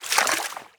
Footstep_Water_03.wav